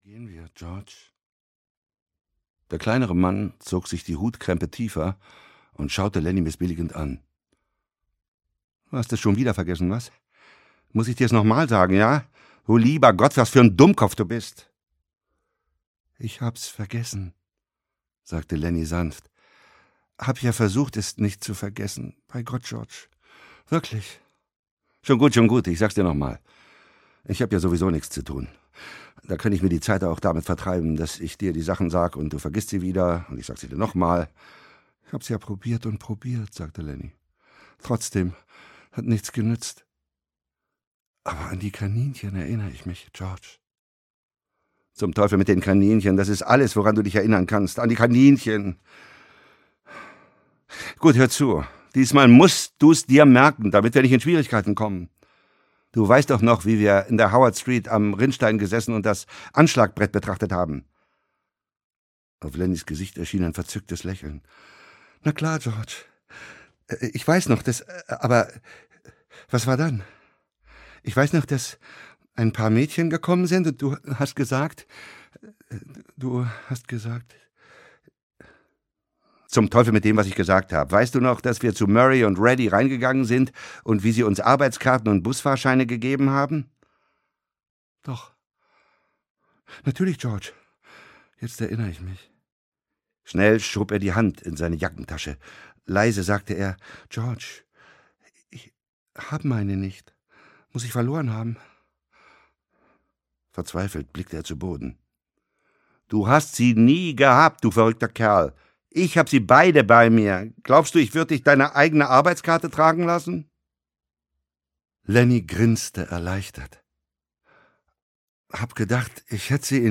Von Mäusen und Menschen - John Steinbeck - Hörbuch